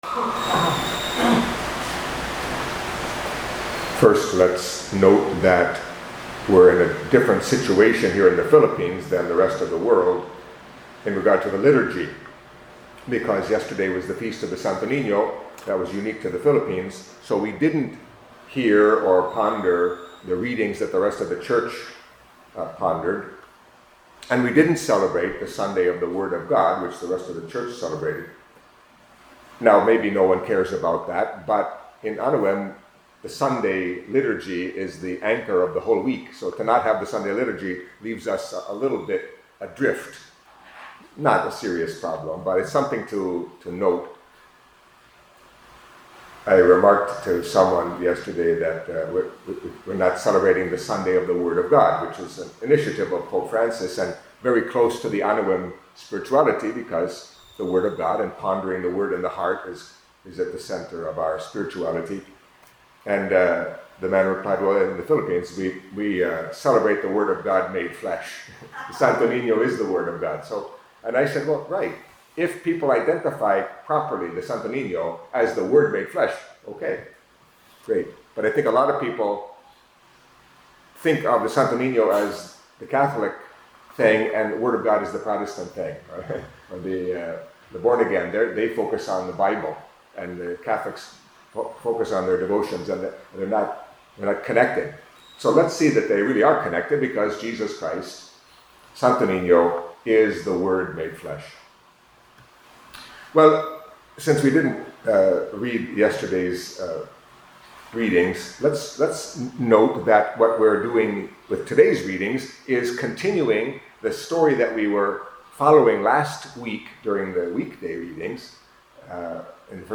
Catholic Mass homily for Monday of the Third Week in Ordinary Time